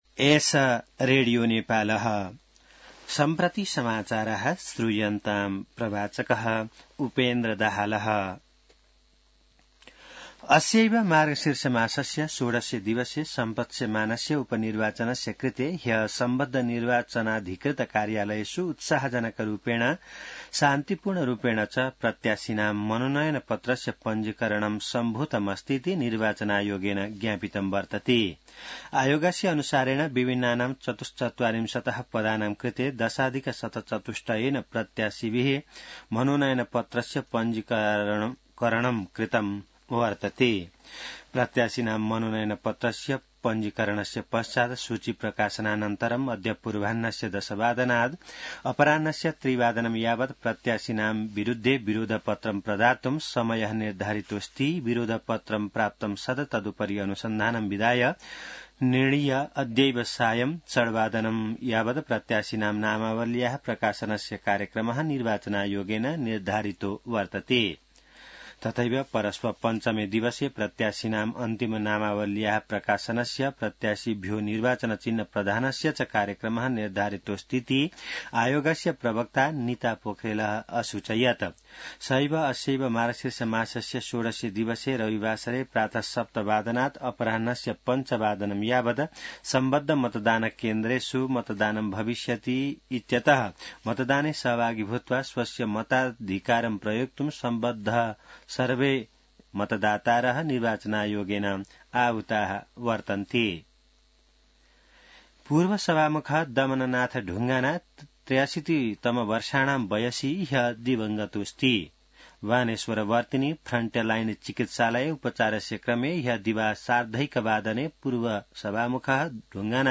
An online outlet of Nepal's national radio broadcaster
संस्कृत समाचार : ४ मंसिर , २०८१